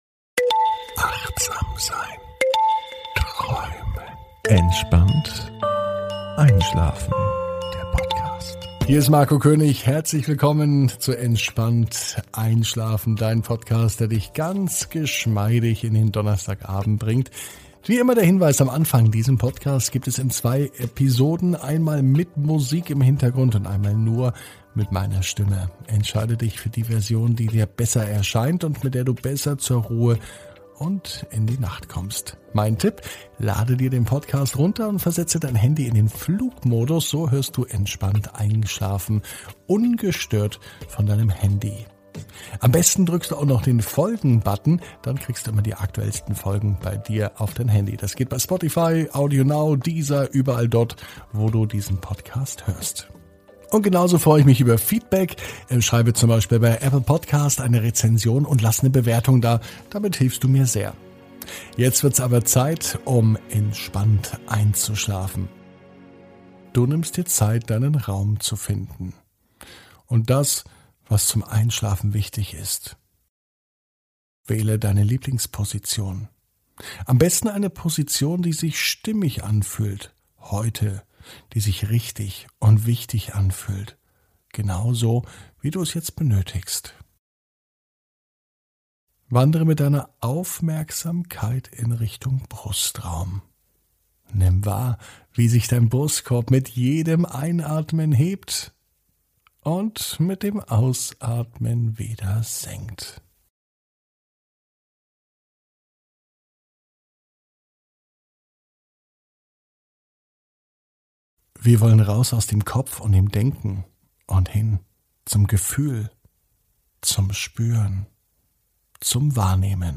(ohne Musik) Entspannt einschlafen am Donnerstag, 13.05.21 ~ Entspannt einschlafen - Meditation & Achtsamkeit für die Nacht Podcast